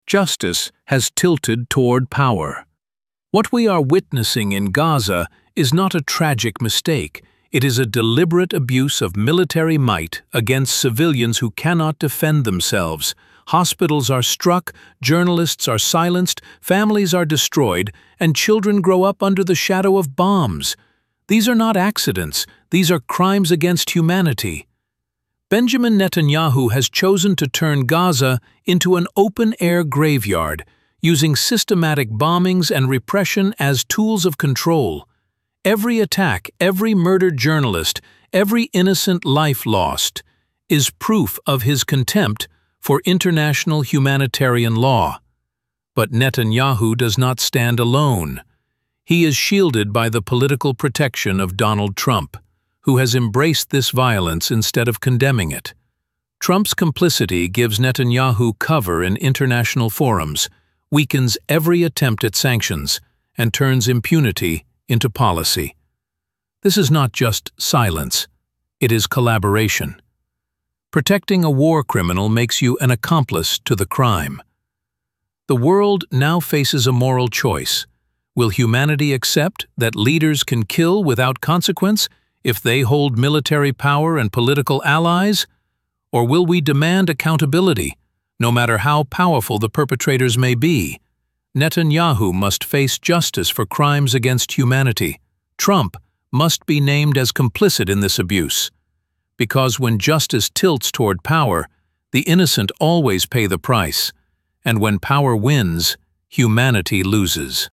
Opinion in Voice — Justice Has Tilted Toward Power